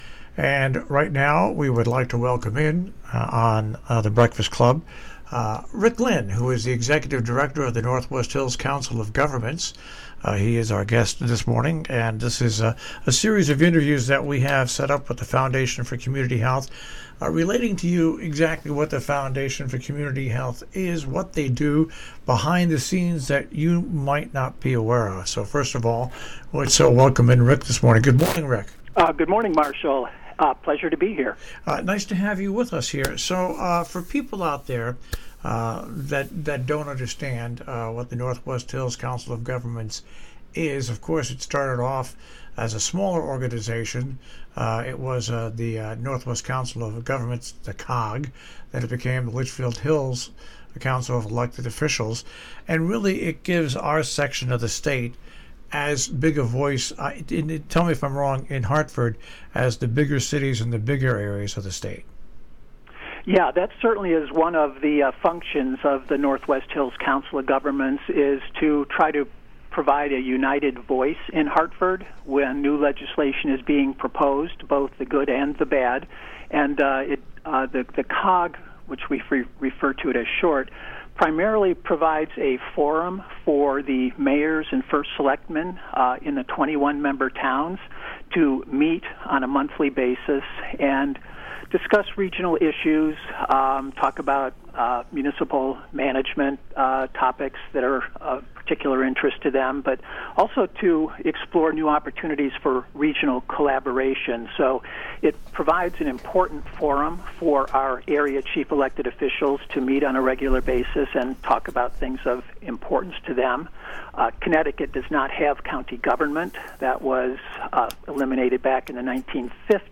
Foundation for Community Health Interview Series